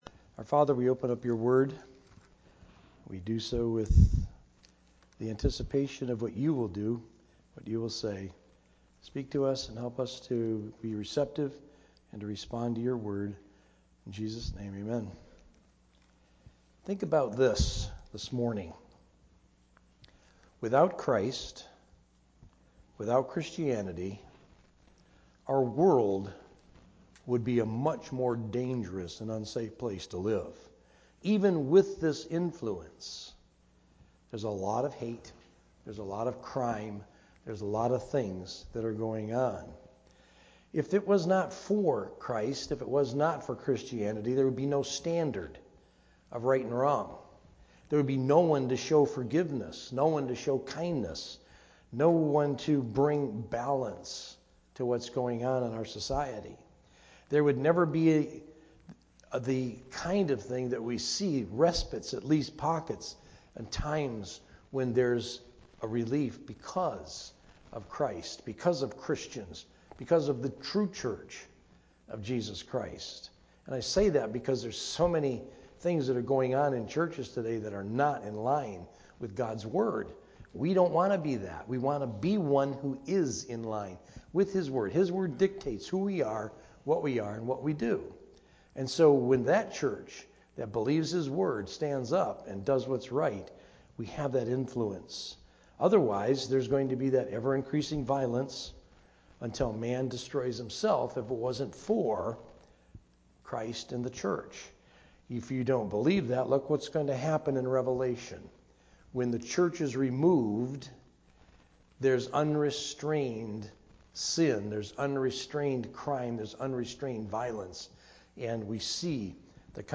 A message from the series "General."
Sermon